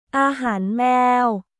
アーハーン・メーオ